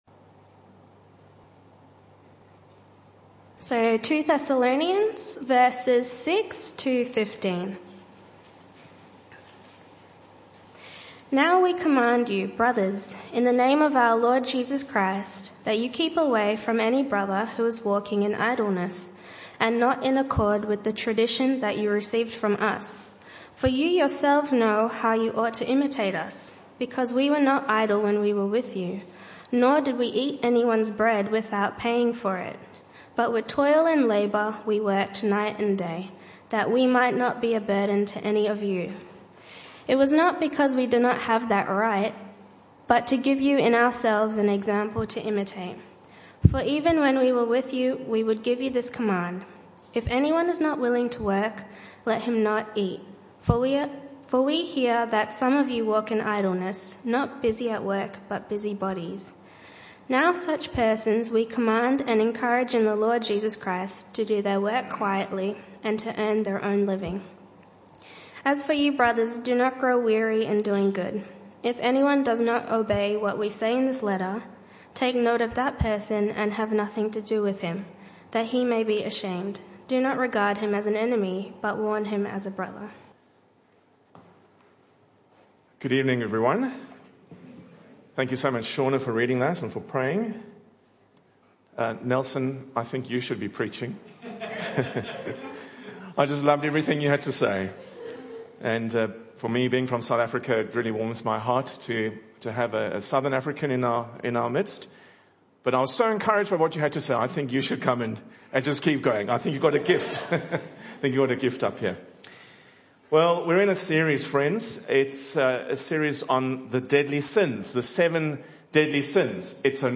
This talk was part of the AM & PM Service series entitled 7 Deadly Sins (Talk 6 of 8).